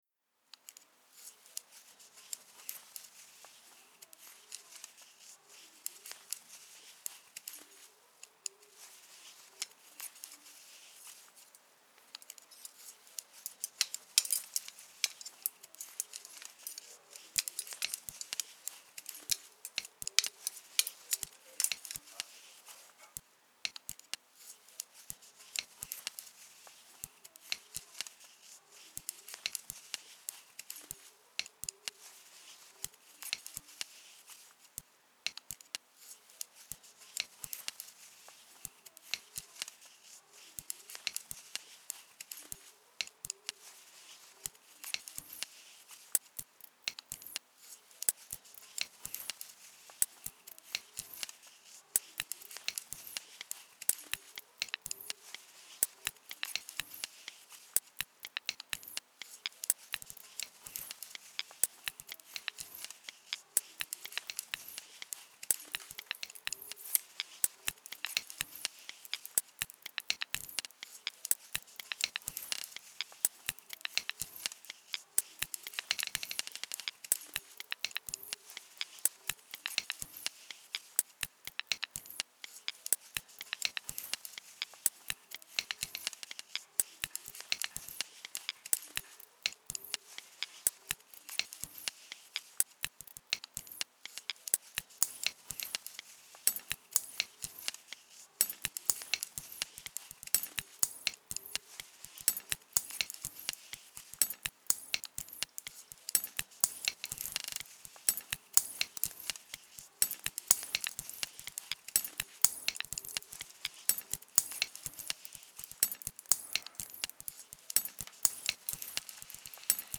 Meditative and practicing the loop.
sound field recordings and synthpads